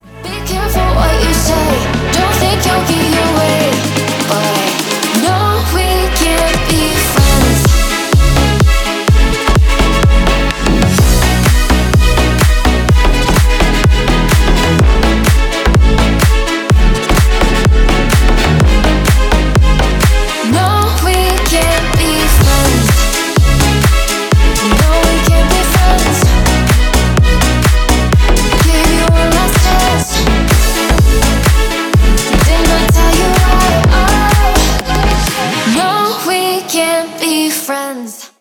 клубные
скрипка